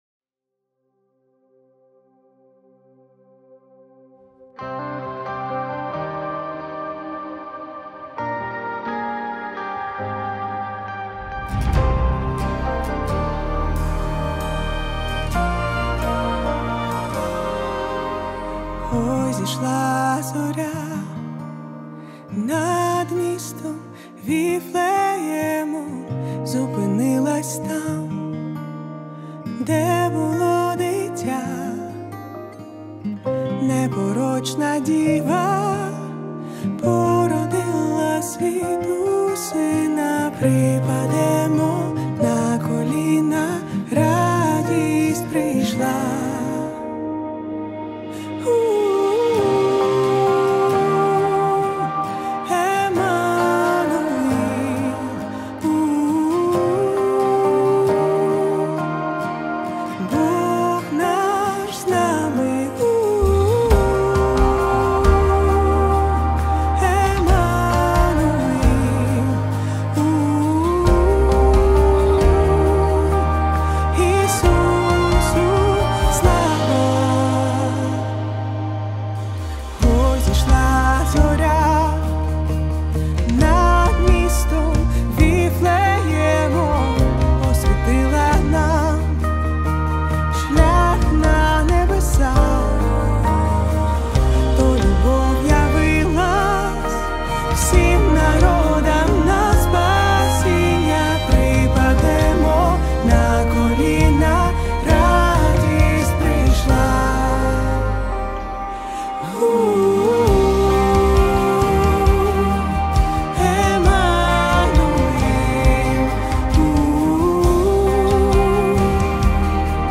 93 просмотра 98 прослушиваний 9 скачиваний BPM: 134